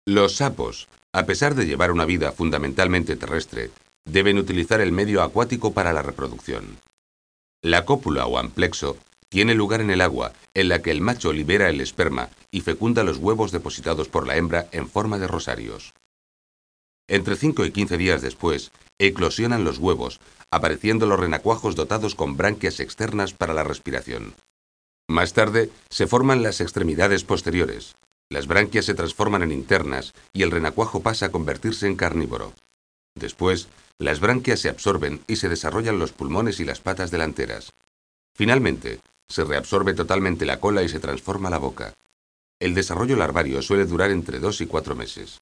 sapo.mp3